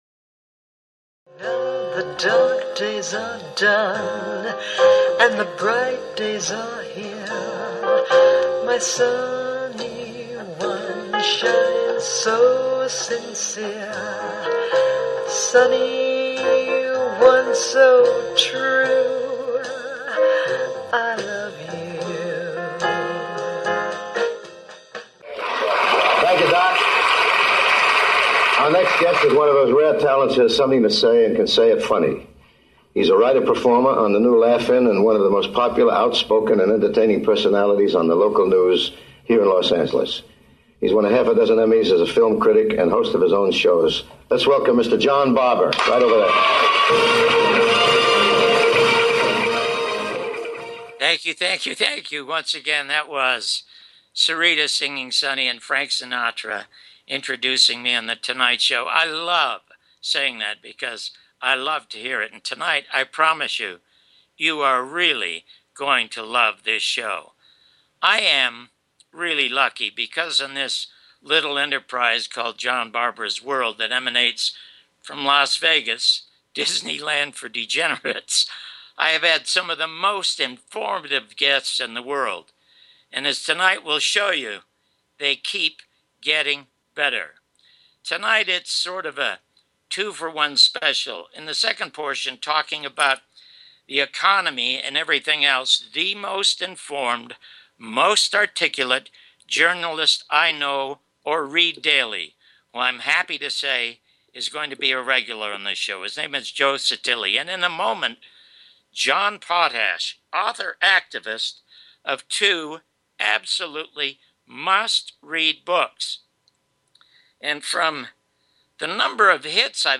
Talk Show Episode
1st half Interview
2nd half interview